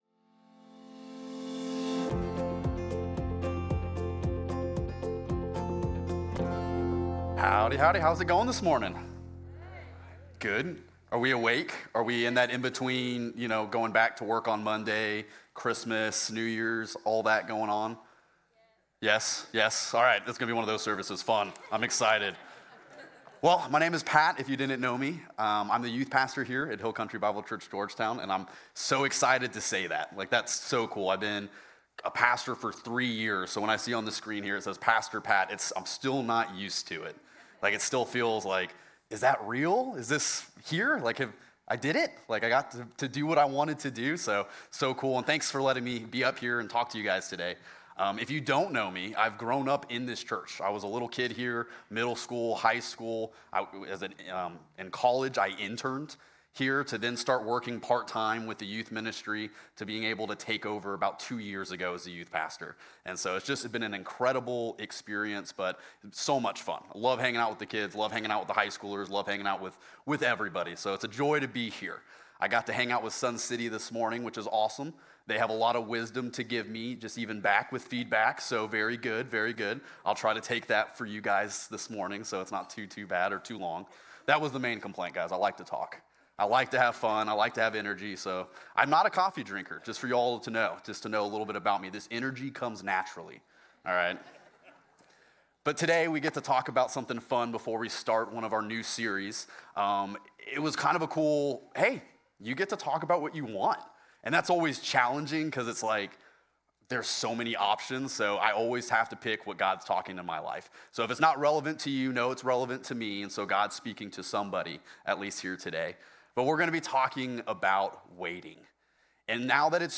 Hill Country Bible Church Sermons
Listen here to the most recent and relevant sermons from Hill Country Bible Church Georgetown.